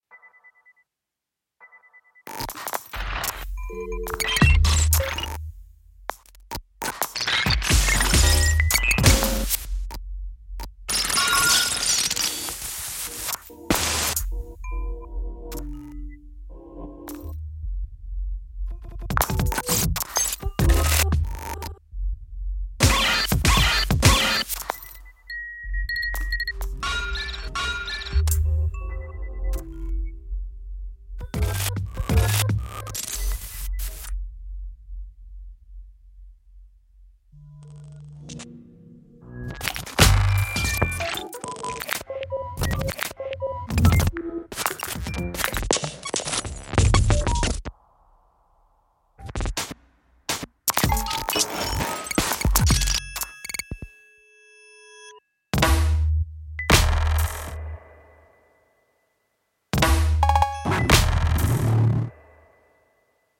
bass clarinet